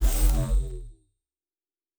Shield Device 3 Stop.wav